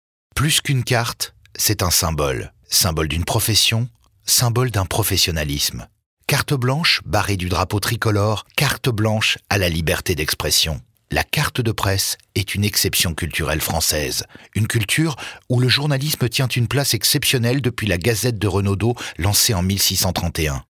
French male voice talent